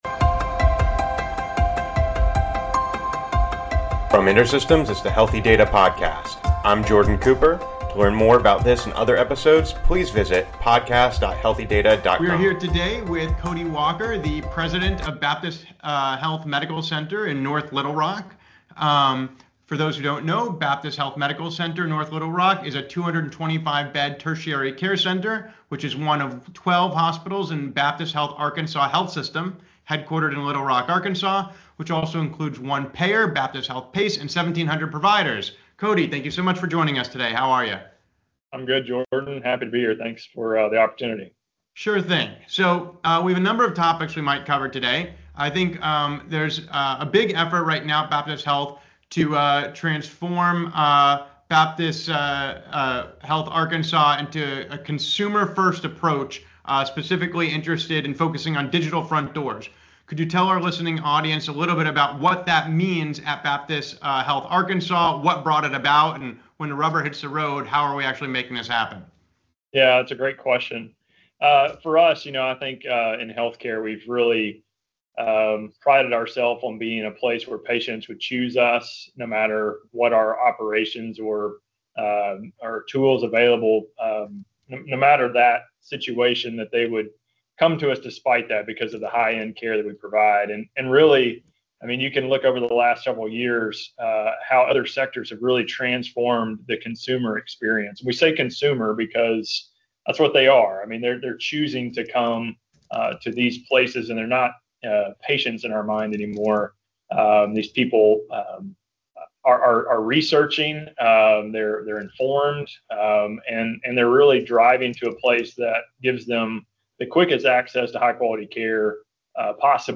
Healthy Data, a podcast series by InterSystems, features conversations with thought leaders in healthcare. Each episode presents new insight into the latest innovations, use cases, and challenges in the intersecting space between healthcare and data.